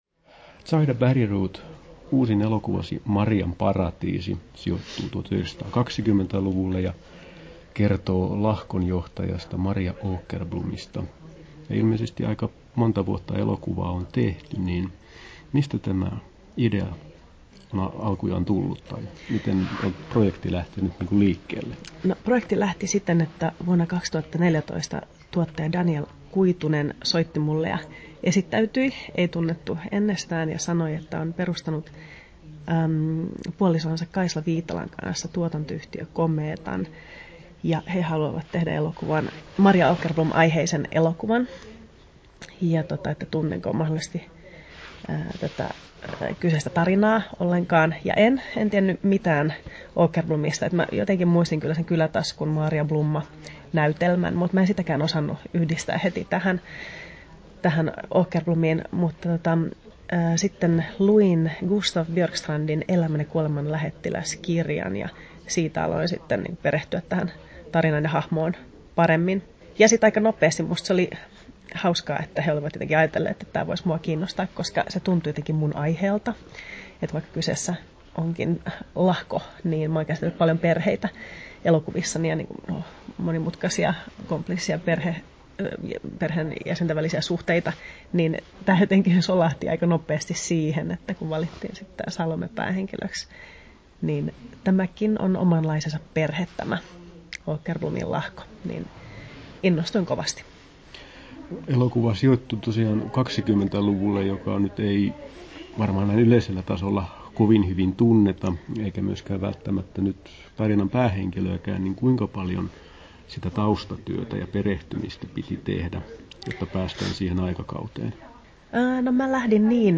Haastattelussa Zaida Bergroth Kesto: 10'12" Tallennettu: 26.09.2019, Turku Toimittaja